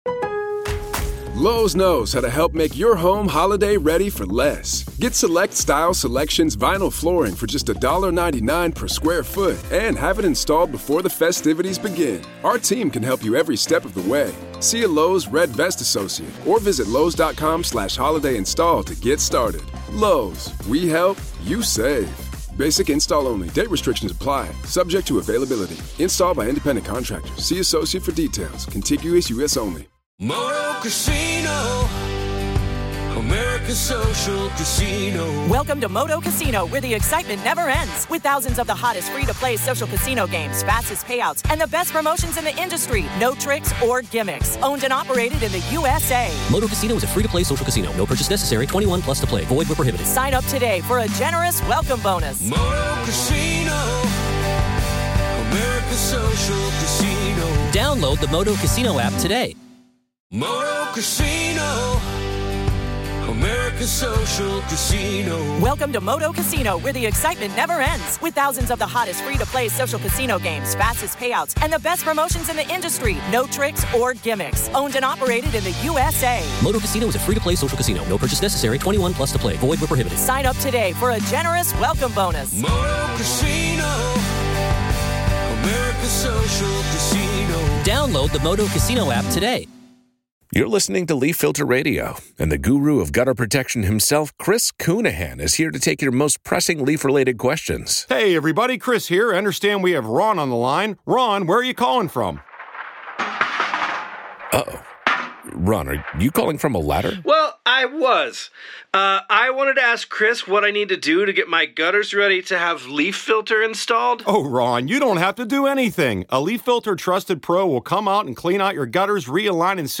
Welcome to a new episode of the Hidden Killers Podcast, where we bring you live courtroom coverage of some of the most gripping and heart-wrenching cases.